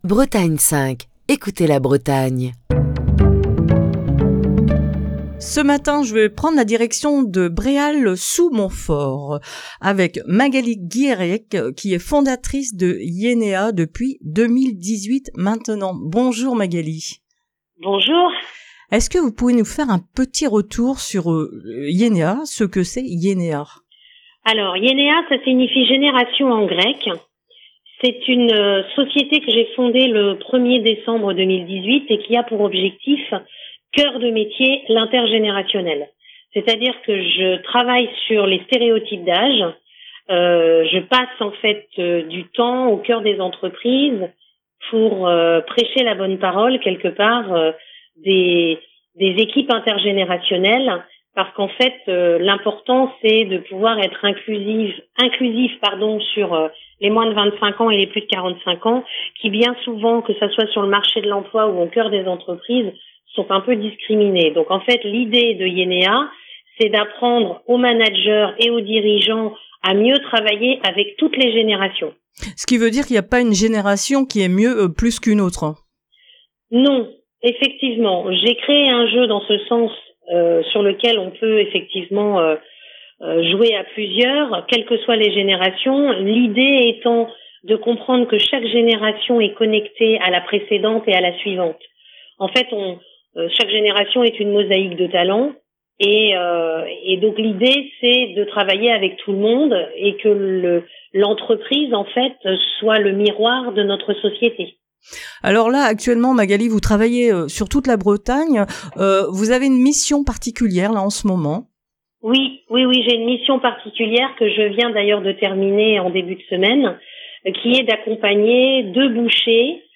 Ce jeudi matin dans le coup de fil